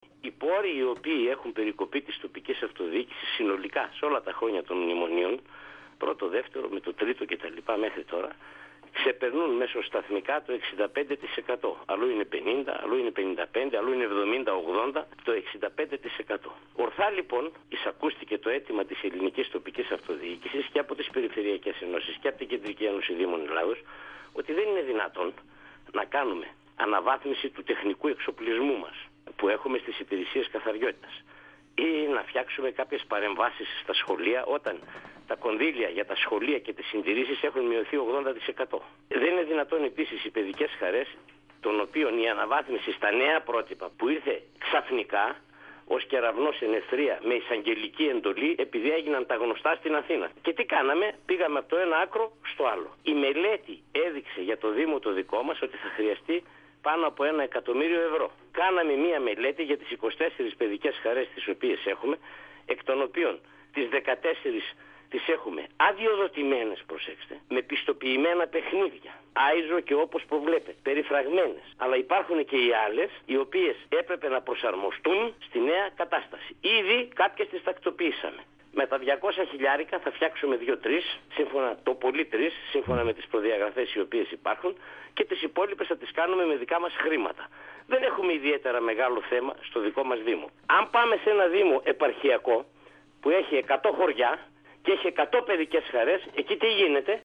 Ο δήμαρχος Αμπελοκήπων Μενεμένης, Λάζαρος Κυρίζογλου, στον 102FM του Ρ.Σ.Μ. της ΕΡΤ3
Συνέντευξη